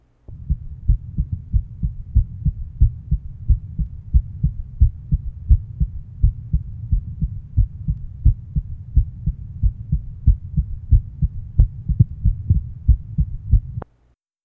Date 1971 Type Systolic Abnormality Rheumatic Heart Disease Good demonstration of low intensity mitral regurgitation murmur in 13 year old post rheumatic fever To listen, click on the link below.